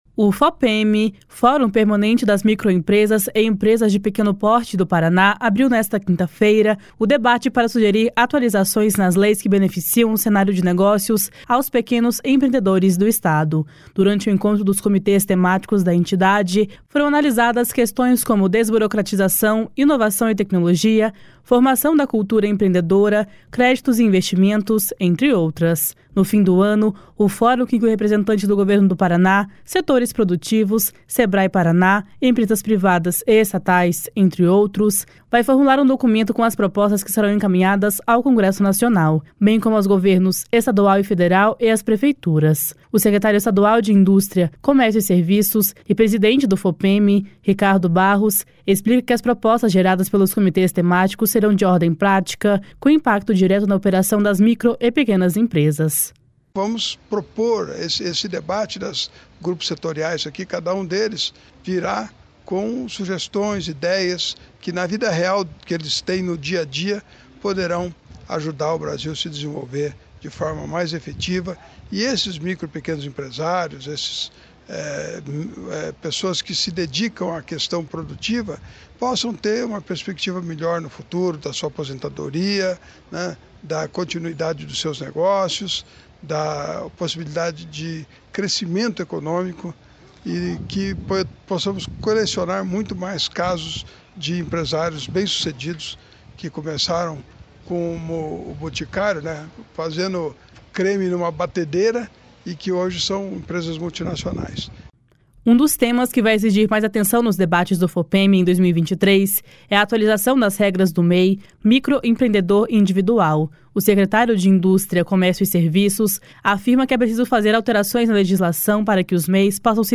O secretário estadual de Indústria, Comércio e Serviços e presidente do Fopeme, Ricardo Barros, explica que as propostas geradas pelos Comitês Temáticos serão de ordem prática, com impacto direto na operação das micro e pequenas empresas.